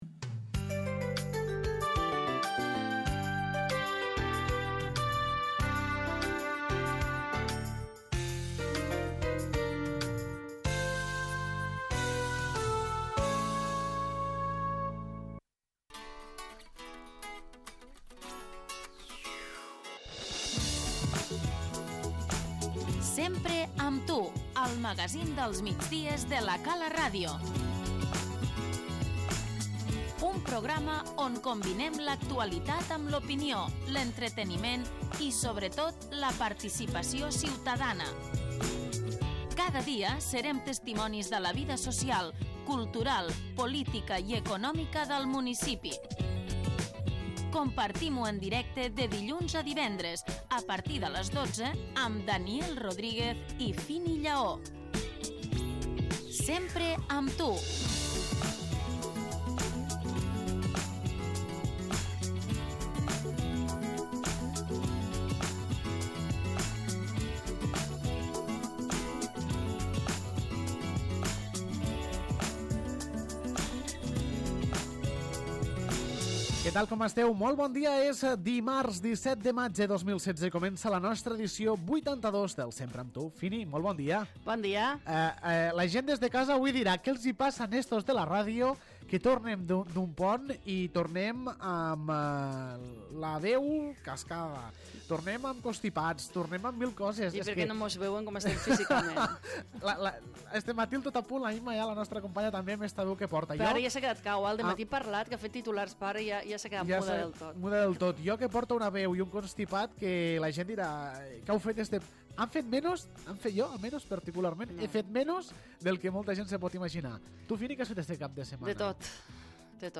LES NOTÍCIES
L'ENTREVISTA